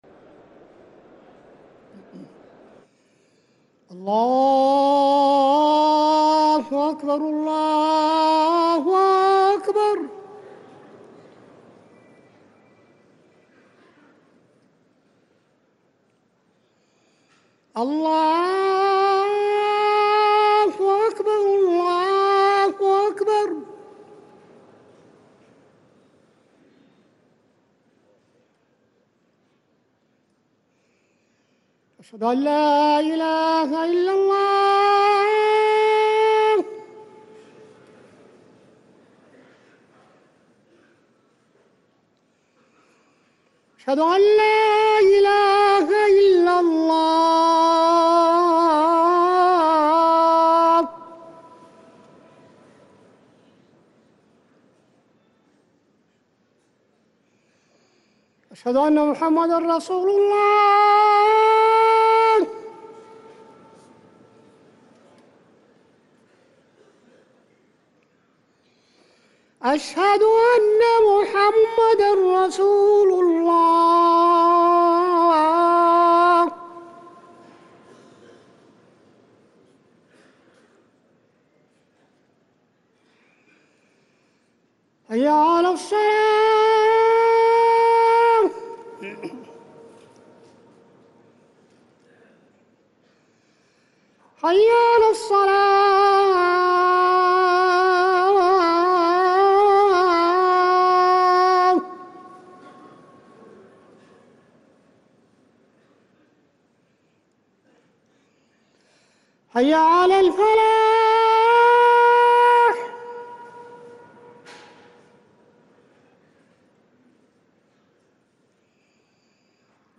أذان العشاء للمؤذن عصام بخاري الثلاثاء 1 شعبان 1444هـ > ١٤٤٤ 🕌 > ركن الأذان 🕌 > المزيد - تلاوات الحرمين